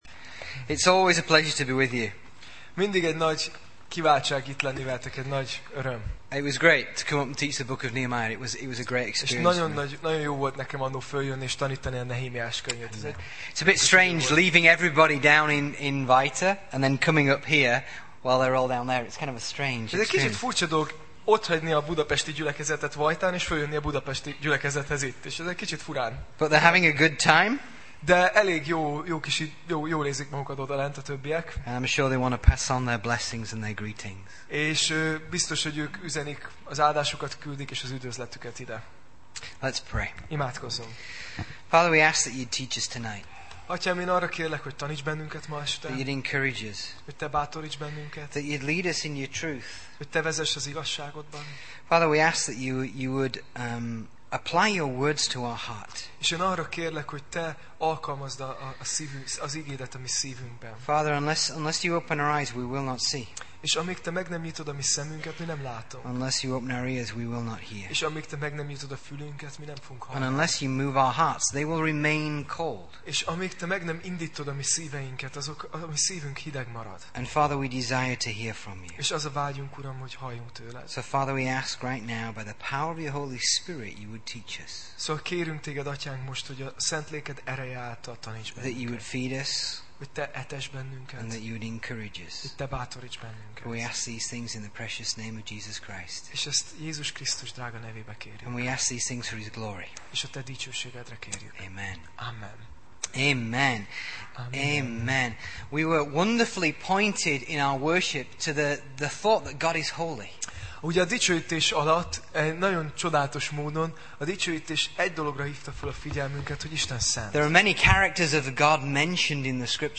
Tematikus tanítás Passage
Alkalom: Szerda Este « Örüljetek az Úrban